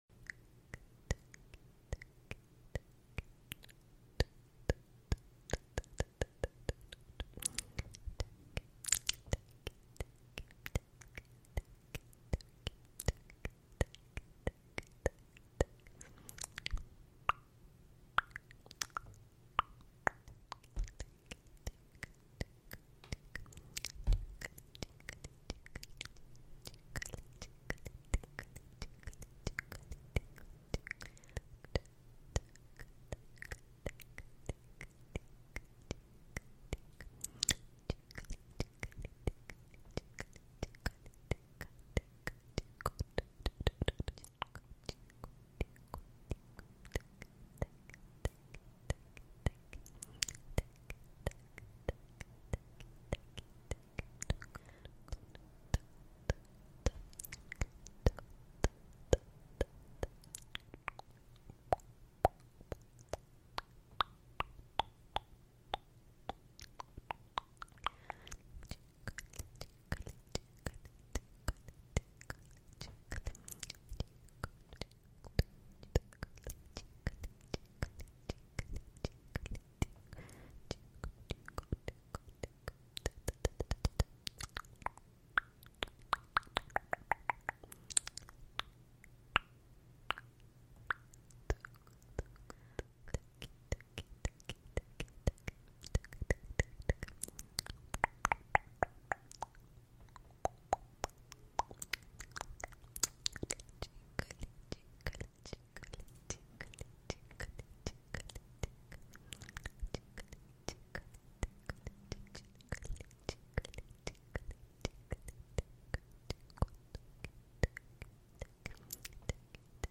asmr - dry mouth sounds | sons de boca secos 👄